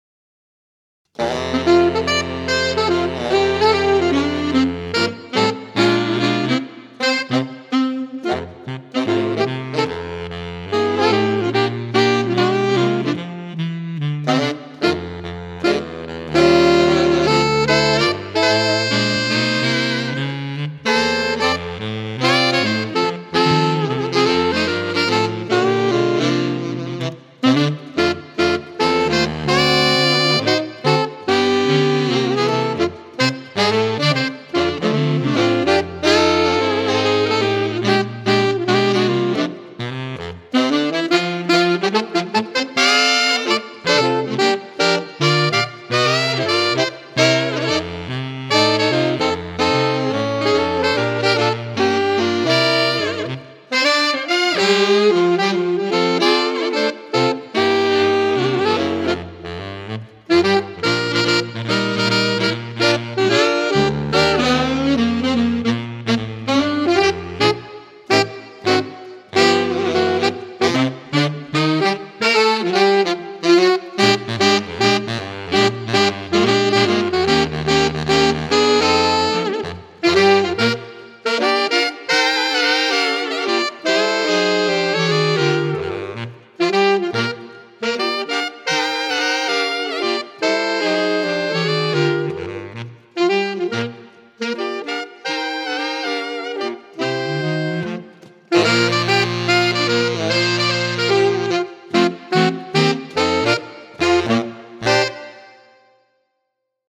Ranges: Alto 1: E3. Tenor: C3. Baritone: C1